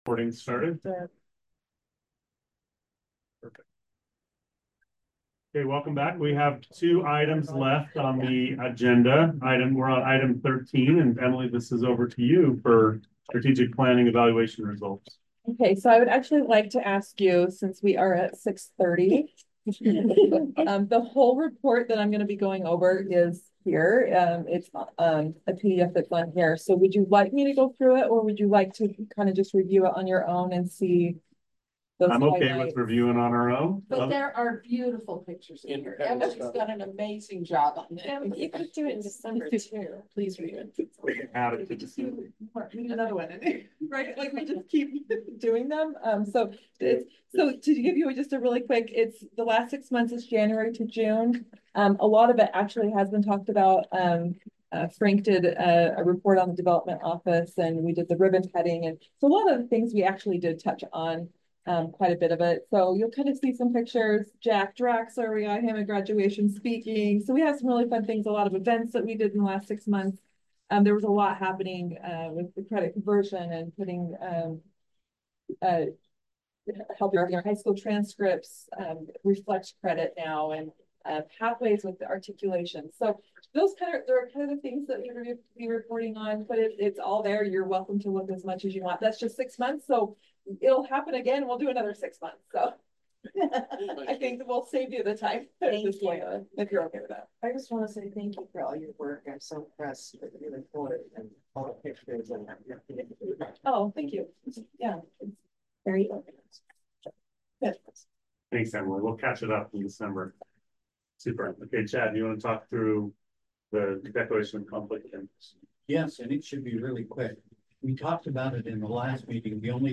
Board of Trustees' Meeting
1301 North 600 West